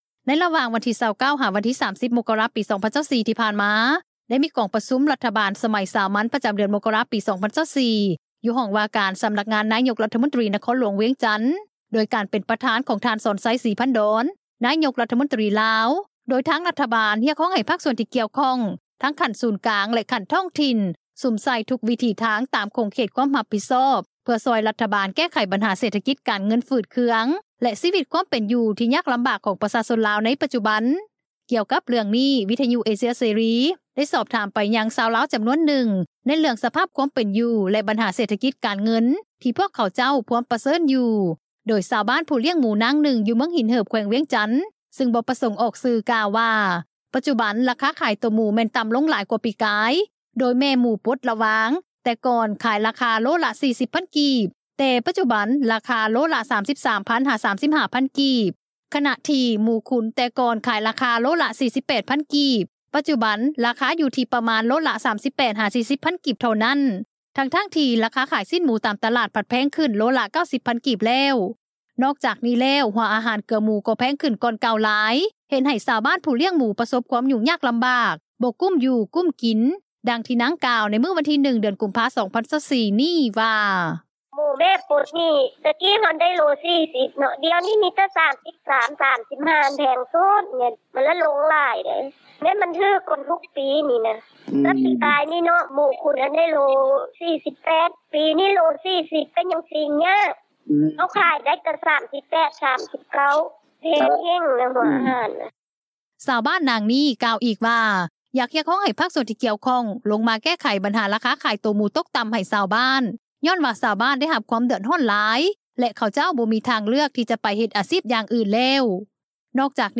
ດັ່ງທີ່ ພະນັກງານຂາຍຣົຖຍົນ ນາງນຶ່ງ ຢູ່ນະຄອນຫຼວງວຽງຈັນ ກ່າວວ່າ:
ຂະນະທີ່ ຍານາງ ທິບພະກອນ ຈັນທະວົງສາ ຮອງຣັຖມົນຕຣີ, ຮອງຫົວໜ້າ ຫ້ອງວ່າການ ສຳນັກງານນາຍົກຣັຖມົນຕຣີ, ຜູ້ປະຕິບັດໜ້າທີ່ໂຄສົກຣັຖບານ ໄດ້ຖແລງຂ່າວ ກ່ຽວກັບ ມາຕການແກ້ໄຂ ວຽກງານເຄັ່ງຮ້ອນທາງດ້ານເສຖກິຈ ໃນກອງປະຊຸມຣັຖບານ ປະຈໍາເດືອນມົກກະຣາ 2024 ຕອນນຶ່ງວ່າ: